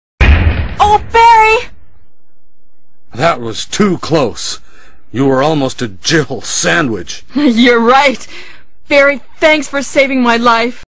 File File history File usage Barry_Dialogue.mp3 (MP3 audio file, length 11 s, 192 kbps overall, file size: 257 KB) Summary edit Media data and Non-free use rationale Description A eleven second long excerpt in-game dialogue from the original Resident Evil game.